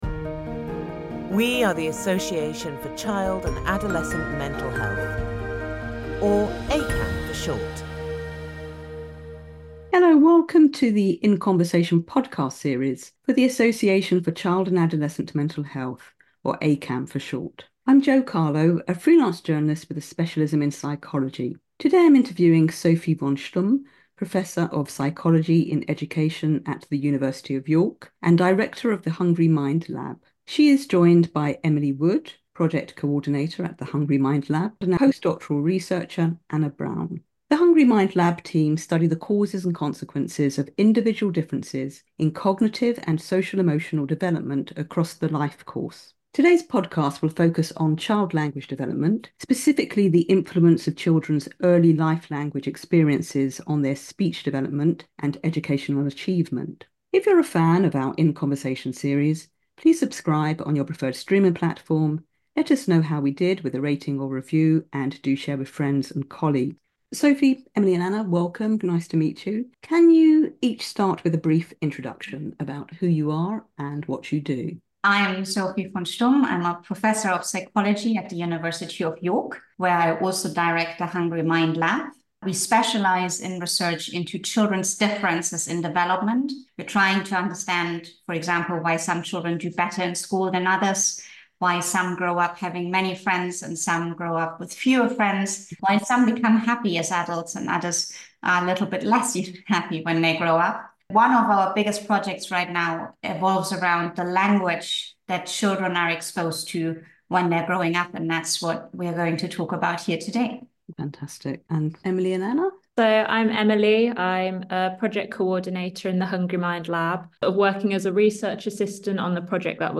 In Conversation